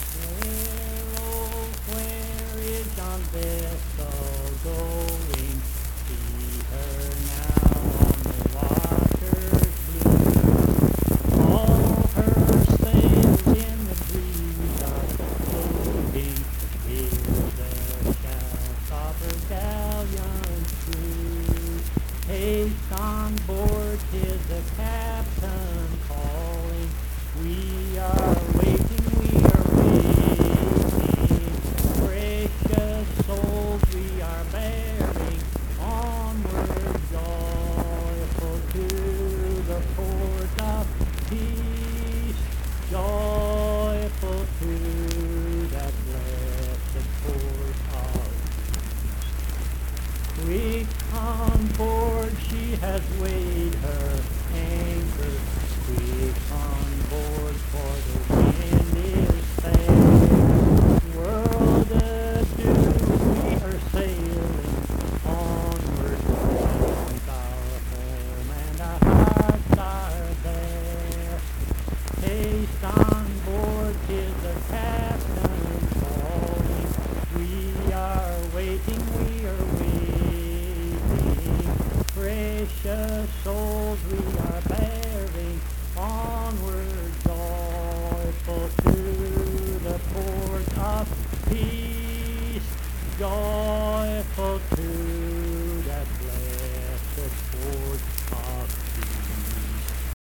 Accompanied (guitar) and unaccompanied vocal music
Performed in Mount Harmony, Marion County, WV.
Hymns and Spiritual Music
Voice (sung)